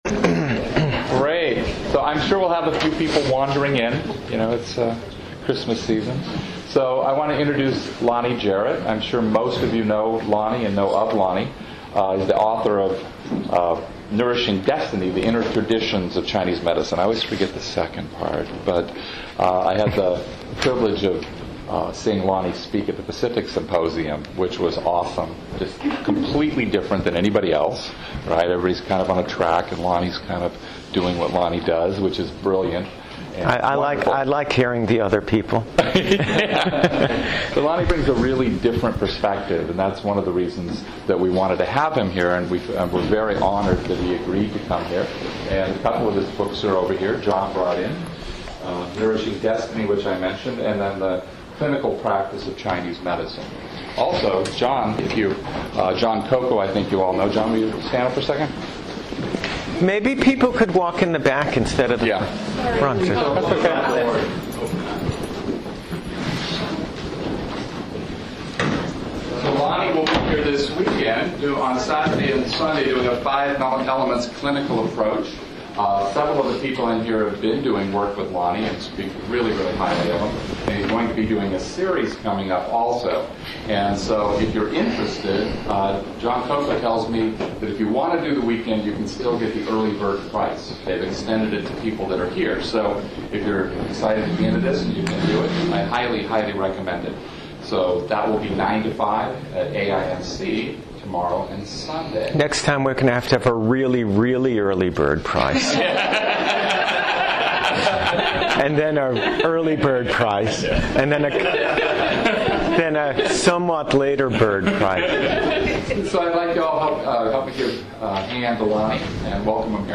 This was a spontaneous talk on the notion that, fundamentally, we are all divided and Chinese medicine is a science of wholeness. Please note the talk at the gentleman at the very end who is caught between being deeply moved by the class and the habit of being abstract in a way that continually removes him from his own higher experience and the beauty he himself is expressing in the moment.